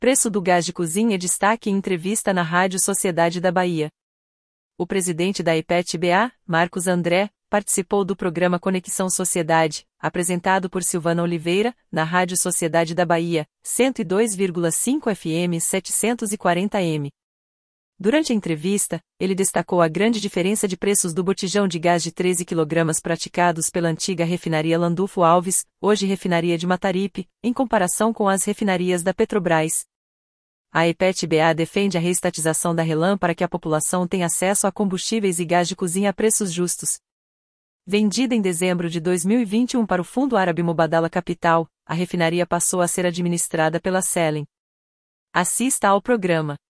Preço do gás de cozinha é destaque em entrevista na Rádio Sociedade da Bahia
Preco-do-gas-de-cozinha-e-destaque-em-entrevista-na-Radio-Sociedade-da-Bahia.mp3